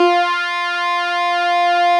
Added synth instrument
snes_synth_053.wav